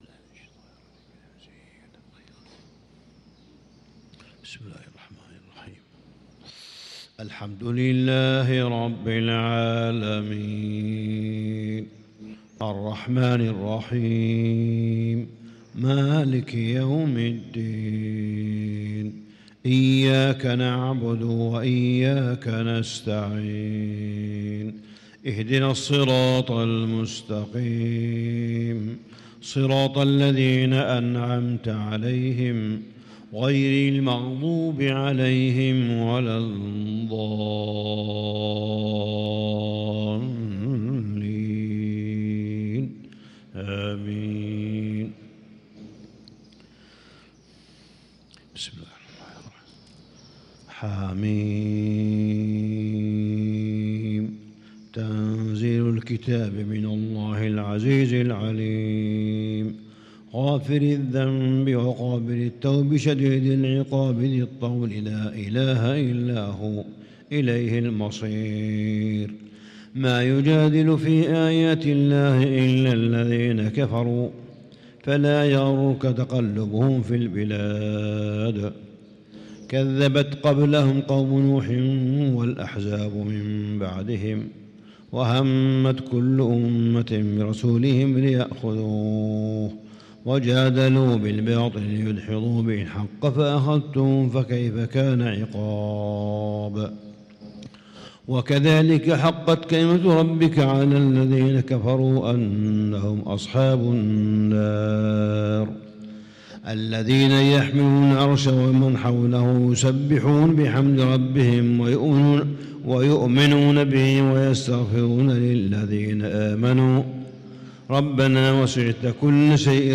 صلاة الفجر للقارئ صالح بن حميد 23 رمضان 1445 هـ